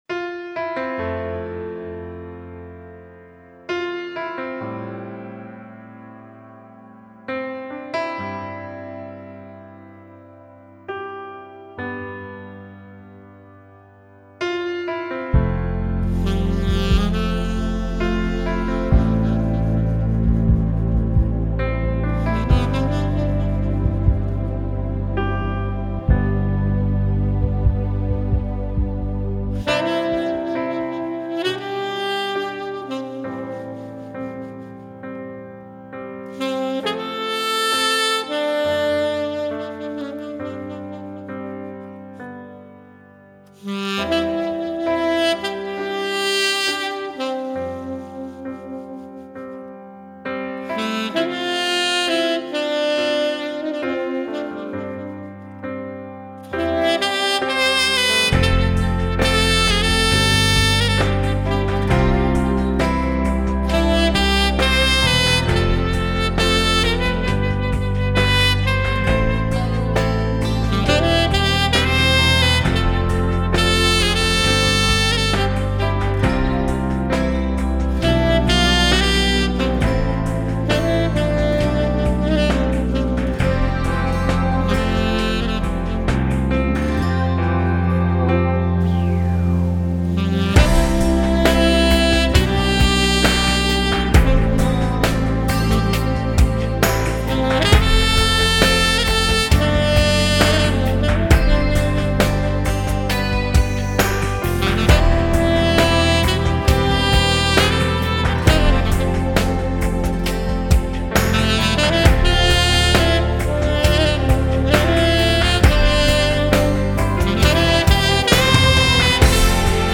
saxofonista